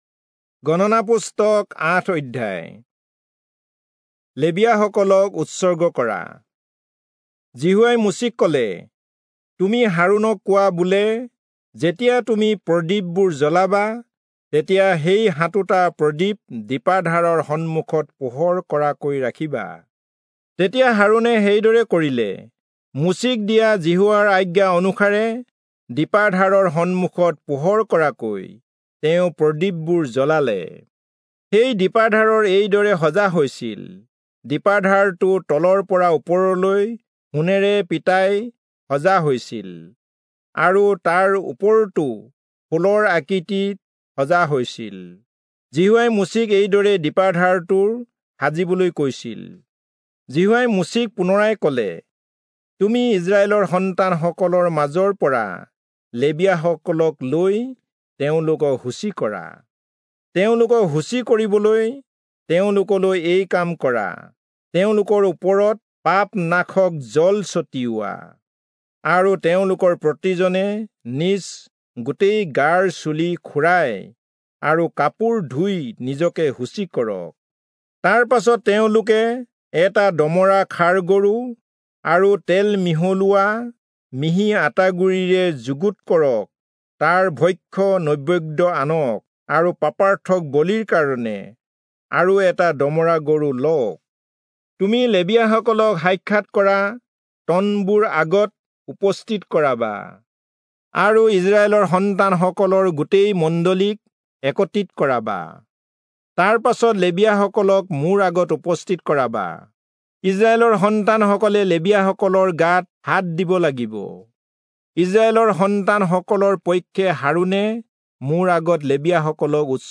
Assamese Audio Bible - Numbers 26 in Alep bible version